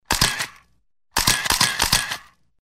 Звуки вспышки фотоаппарата
Звук щелчка камеры